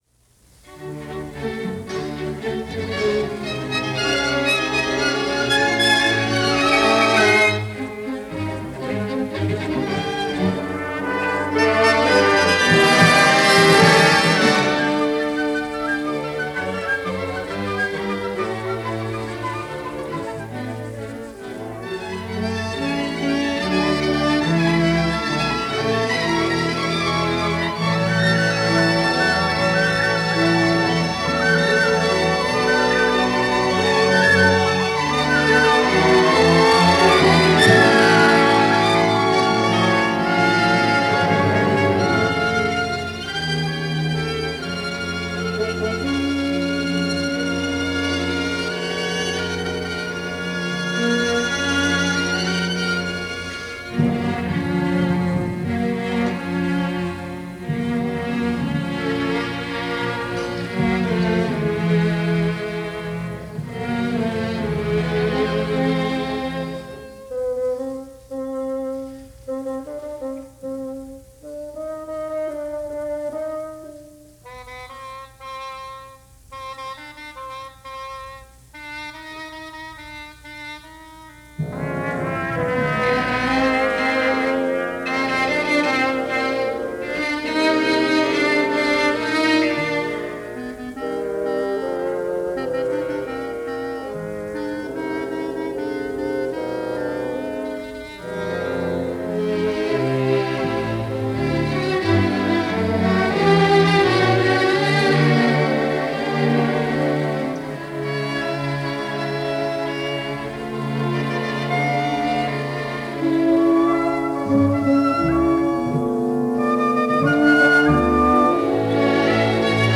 NBC Studio Orchestra
His compositions drew inspiration from Chilean folk themes as well as the music of the Mapuche.